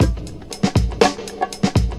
リズム・アレンジ系
サンプルの途中から再生を始めるコマンド。
[0S80] はサンプルのちょうど真ん中から再生され、[0S40] はサンプルの前から25%の位置から再生されます。
lesson6-beat-01.mp3